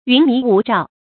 云迷霧罩 注音： ㄧㄨㄣˊ ㄇㄧˊ ㄨˋ ㄓㄠˋ 讀音讀法： 意思解釋： 見「云迷霧鎖」。